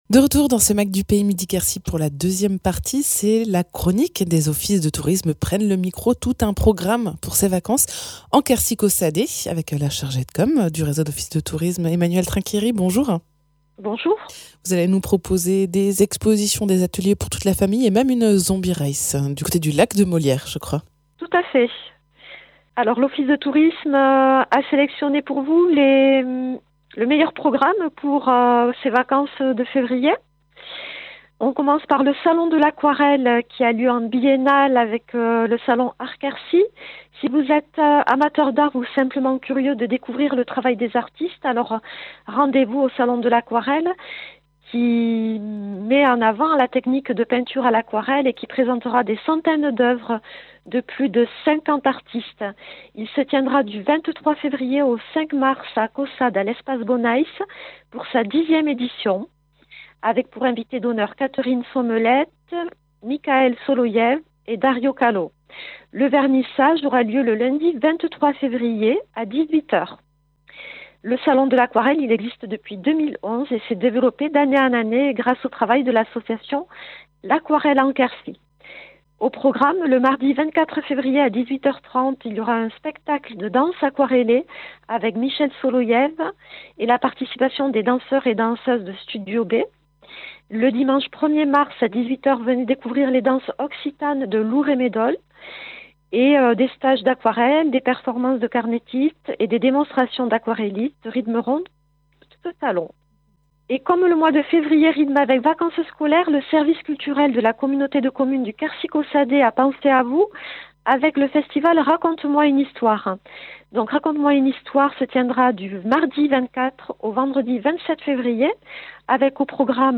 Envie de visites, de sorties, de salons ou d’ateliers pour les enfants pour ces vacances ? Ecoutez la chronique des offices prennent le micro en Quercy Caussdais !